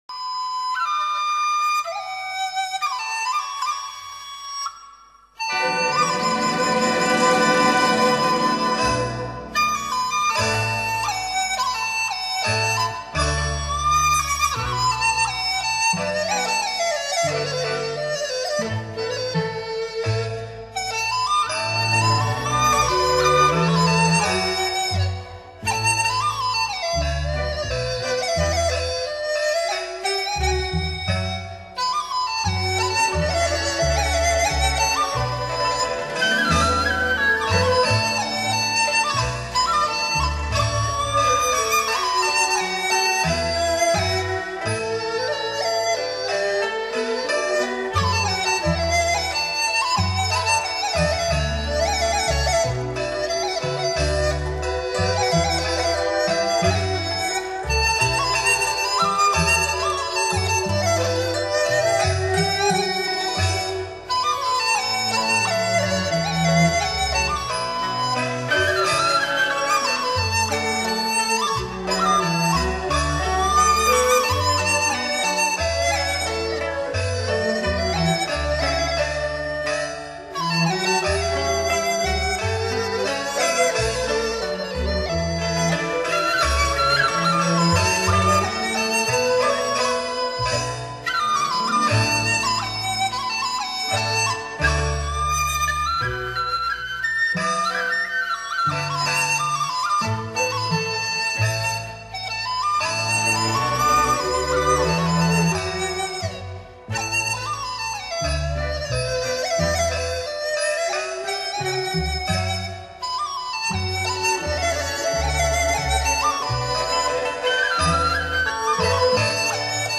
这些乐曲中，有相当一部分是50年代或60录制的，由于年代较远，音响效果不甚理想，然而，历史性录音的珍贵价值弥足珍贵。
广东音乐